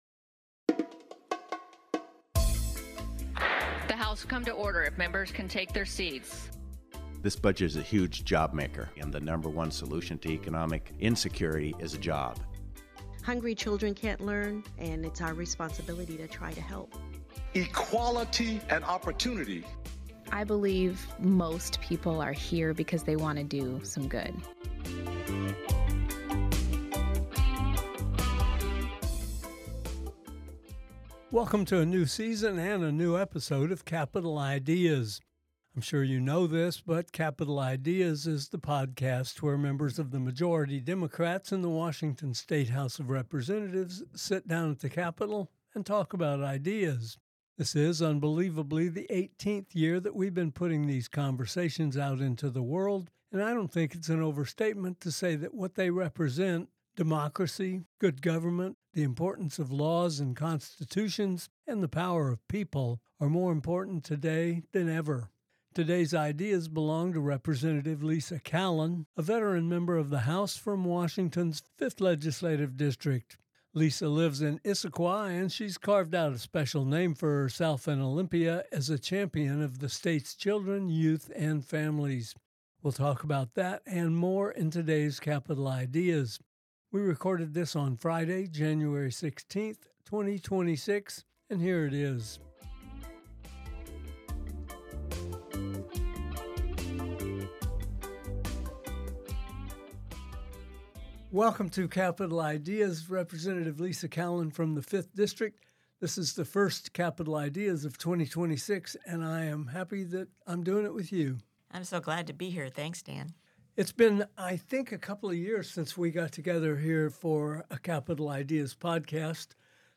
Washington’s young people have a lawmaker in their corner 24/7/365. Rep. Lisa Callan visits Capitol Ideas to talk about her priorities for 2026, including the progress of Washington Thriving, a comprehensive program she created in 2022.